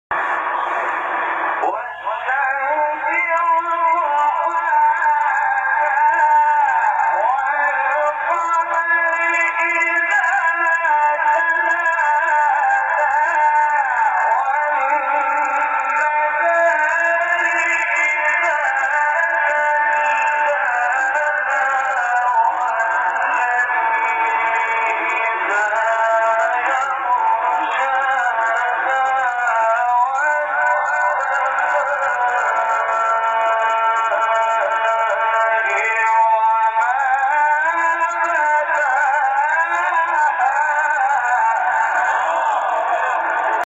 تلاوت قدیمی و زیبای شمس محمود شحات | نغمات قرآن
مقام: بیات